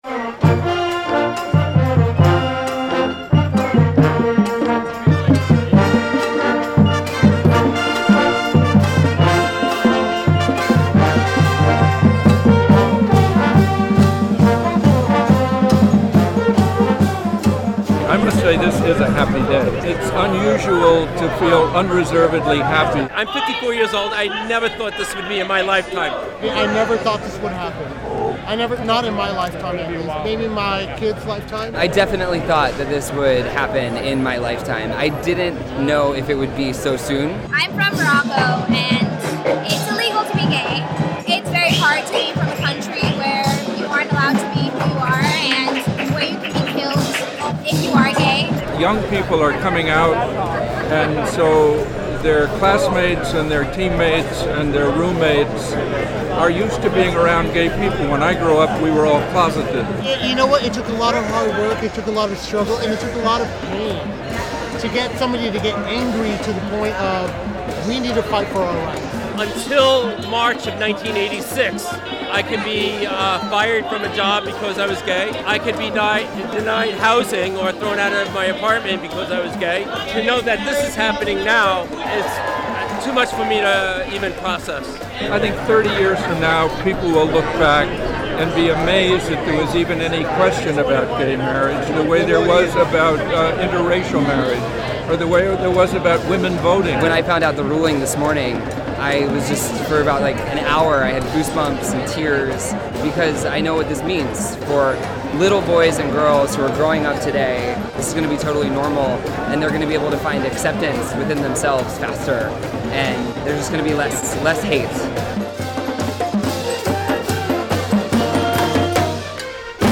Thousands celebrate around NYC dive bar where the American gay rights movement was born 46 years ago this weekend.
Reason TV once again reported from the jubilant scene around New York City's Stonewall Inn, the once-notorious gay dive bar widely recognized as the birthplace of the American gay rights movement, asking the celebrants what the moment means and what comes next.